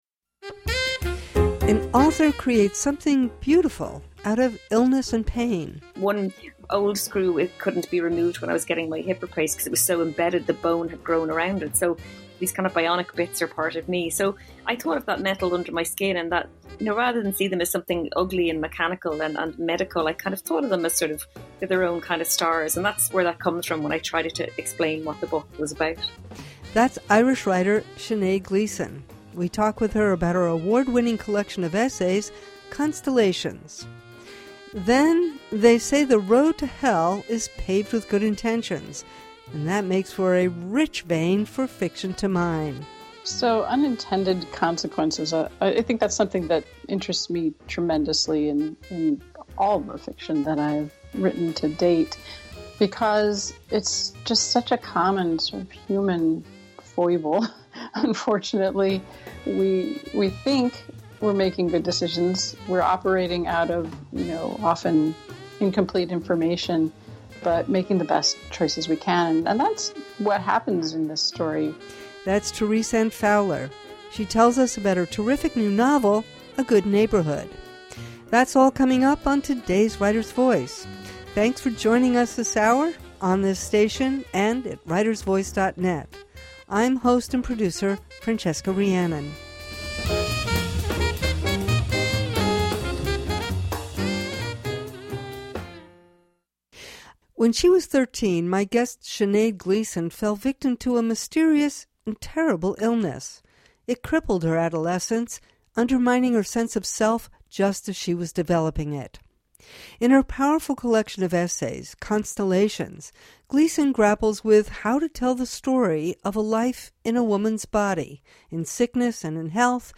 Writer’s Voice — in depth conversation with writers of all genres, on the air since 2004.